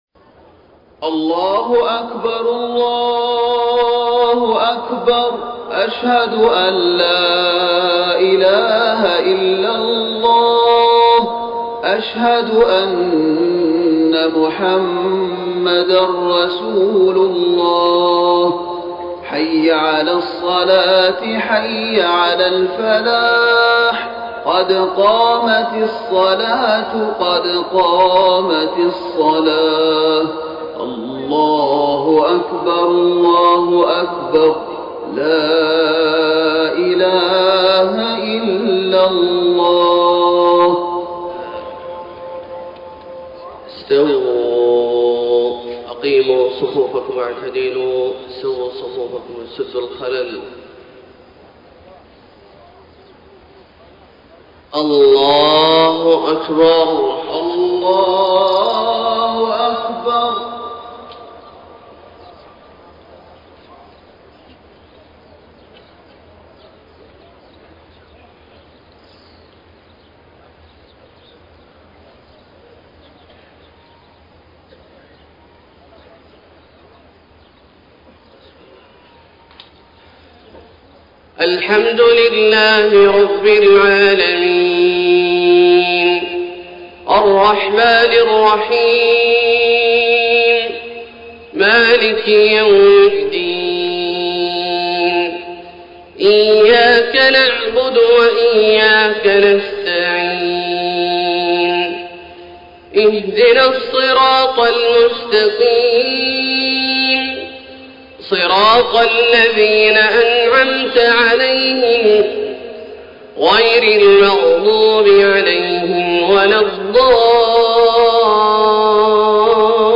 صلاة الفجر 4 - 4 - 1434هـ من سورة المائدة > 1434 🕋 > الفروض - تلاوات الحرمين